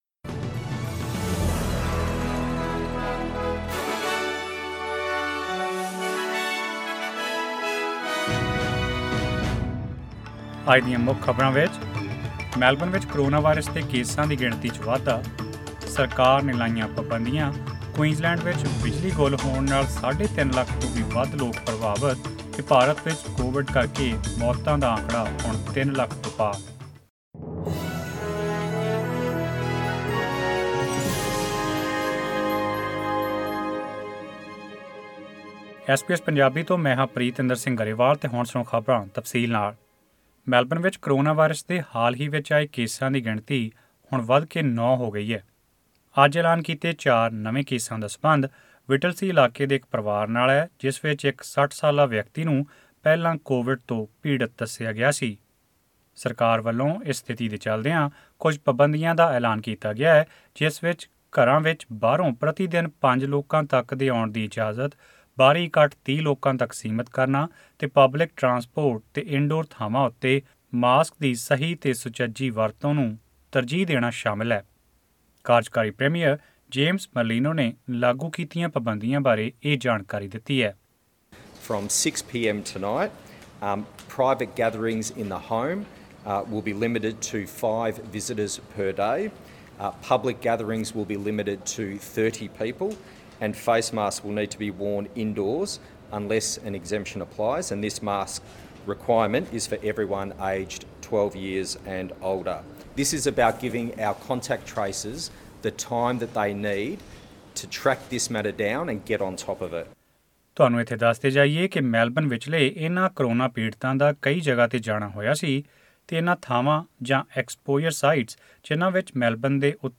This and more in this news bulletin.